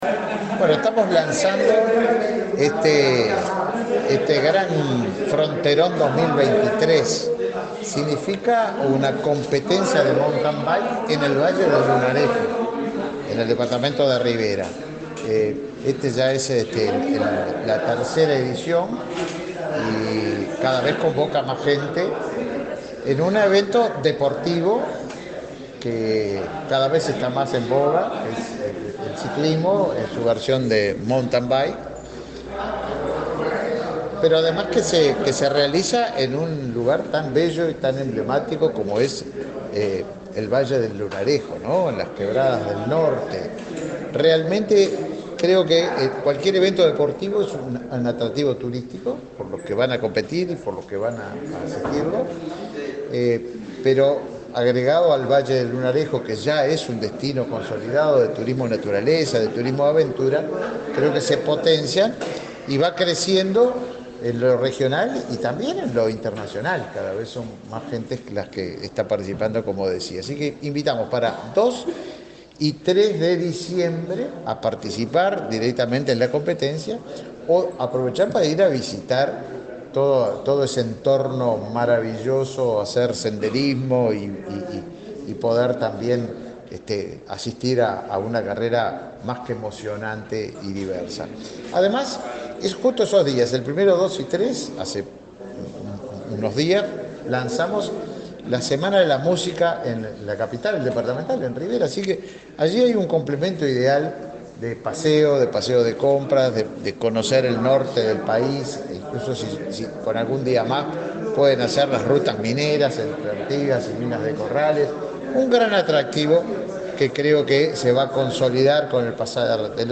Declaraciones del ministro de Turismo, Tabaré Viera
Declaraciones del ministro de Turismo, Tabaré Viera 20/11/2023 Compartir Facebook X Copiar enlace WhatsApp LinkedIn Este lunes 10 en Montevideo, el ministro de Turismo, Tabaré Viera, dialogó con la prensa, luego de participar del lanzamiento de la final del campeonato internacional Montain Bike Fronteron 2023.